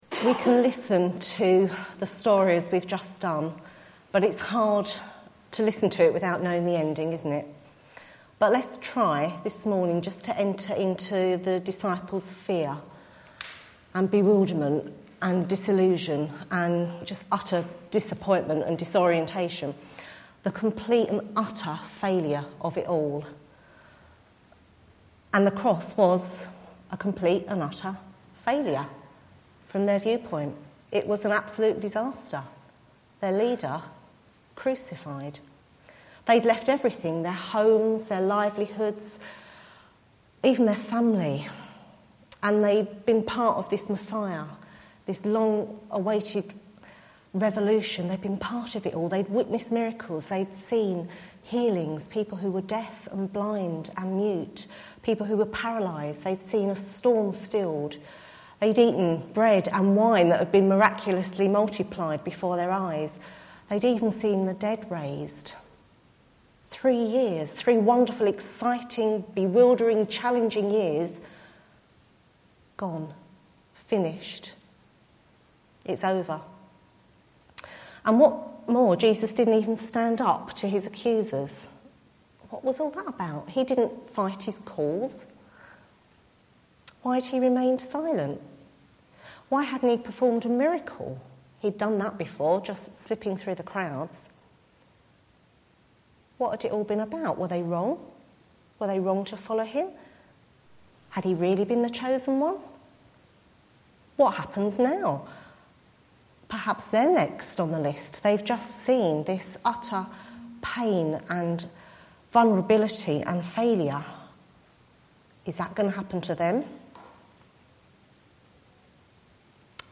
mark 15:1-39 Service Type: Sunday Morning « Jesus’s Restoration of all the People